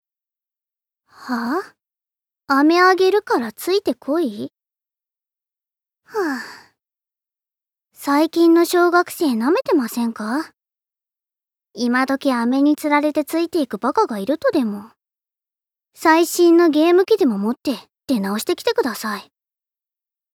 ボイスサンプル
セリフ３